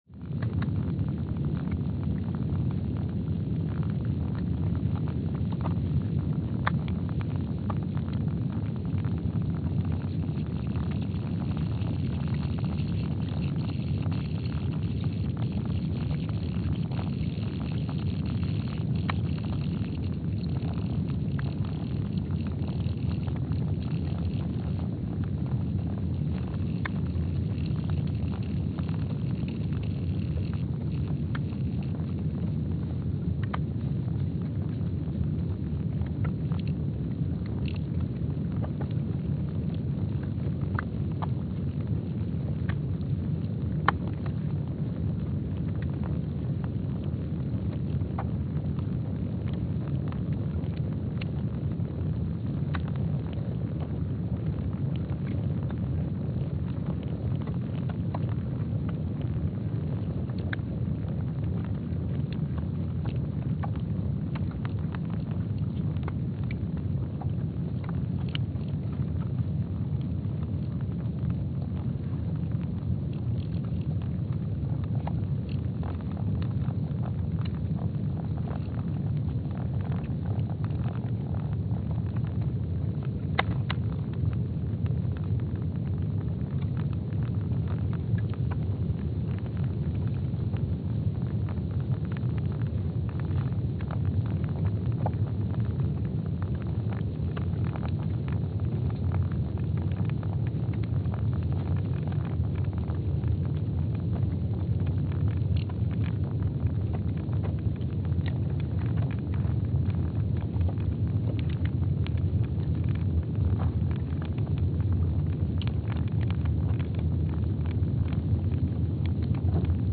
Palmer Station, Antarctica (seismic) archived on May 19, 2025
Sensor : STS-1VBB_w/E300
Speedup : ×500 (transposed up about 9 octaves)
Loop duration (audio) : 05:45 (stereo)
SoX post-processing : highpass -2 90 highpass -2 90